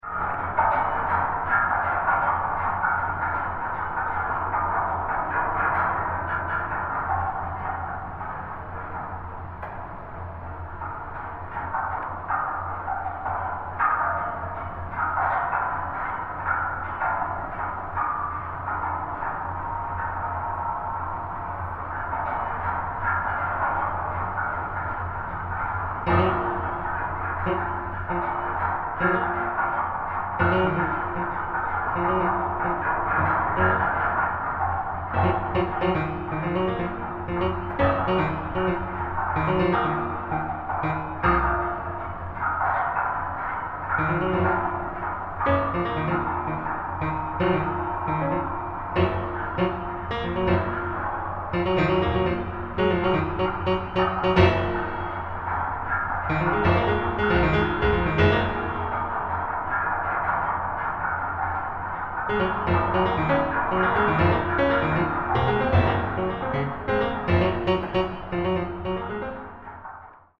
for solo piano, transducers, and field recordings